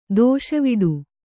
♪ dōṣaviḍu